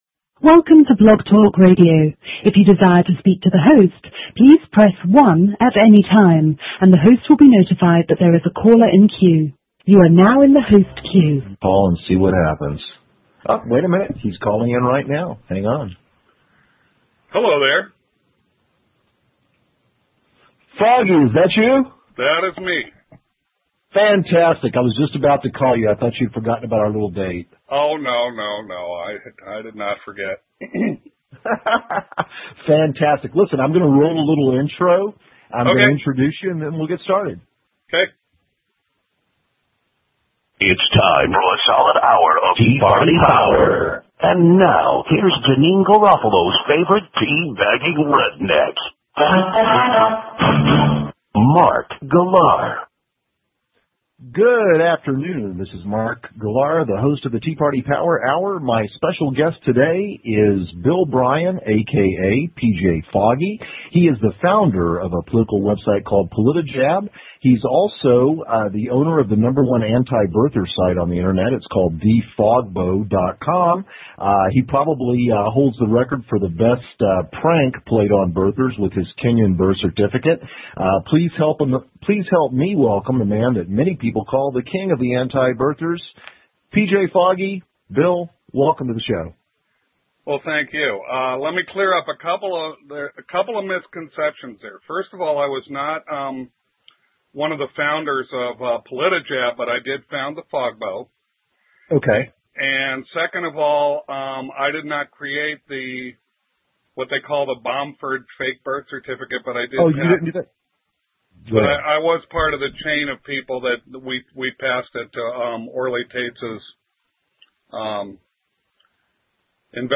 Fair warning: the MP3 is 14 MB in size (two hour interview).
We had a nice friendly conversation, but he was told by Corsi not to have me on his show again.